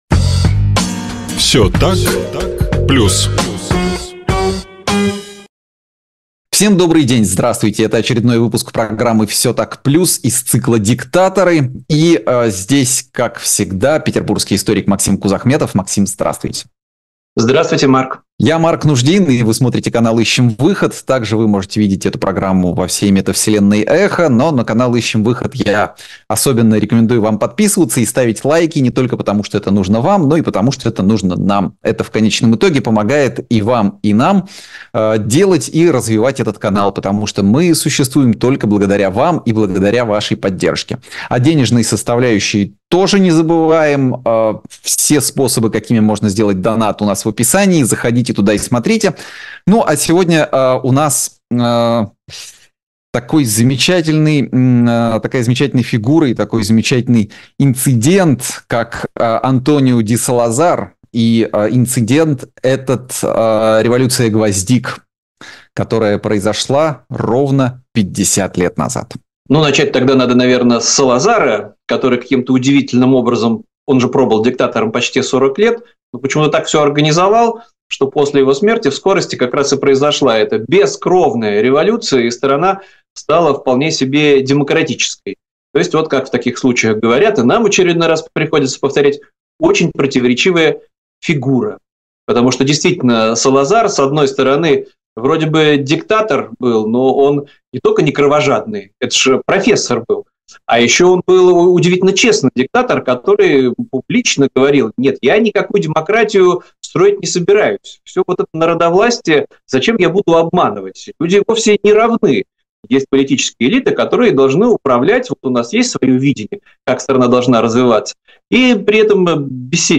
историк
журналист